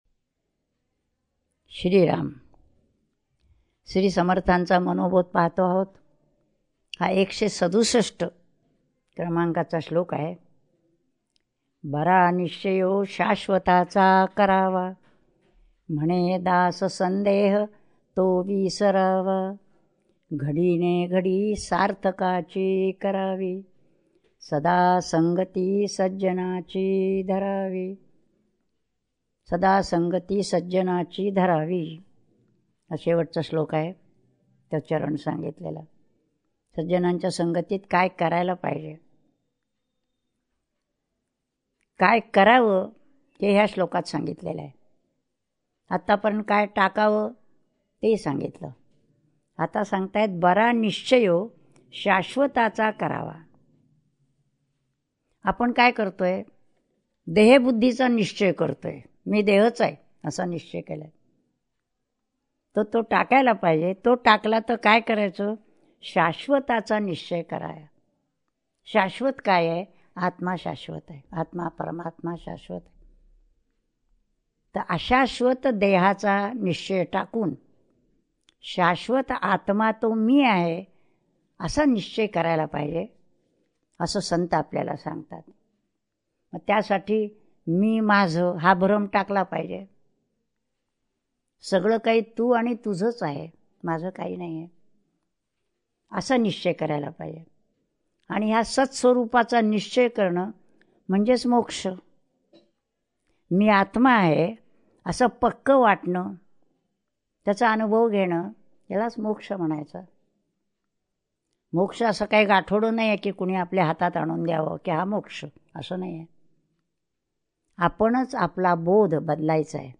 श्री मनाचे श्लोक प्रवचने श्लोक 167 # Shree Manache Shlok Pravachane Shlok 167